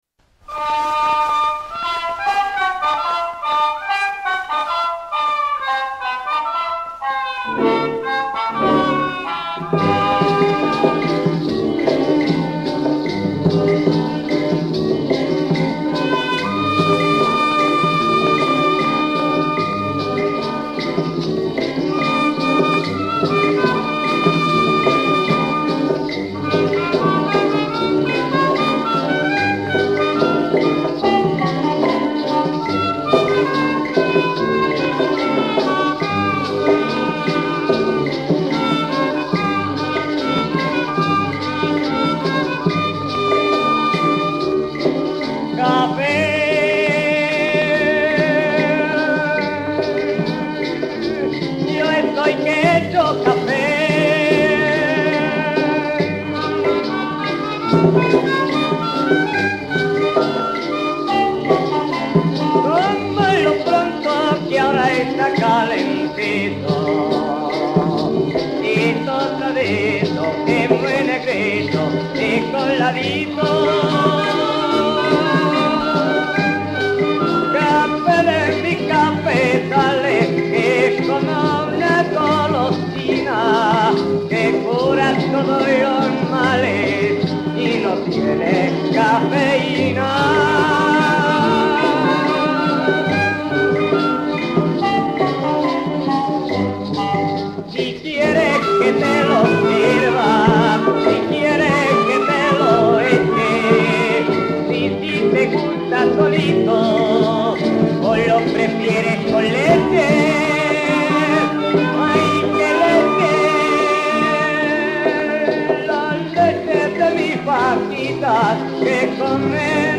Antonio Palacios singsJazz band:
Next, he went to San Juan in Puerto Rico, where in 1914 he made his debut as a singer – first, unsuccessfully, as a lyrical tenor in the zarzuelas El cabo primer (Fernández Caballero) and La revoltosa (Chapí), soon as a – highly successful – "tenor comico".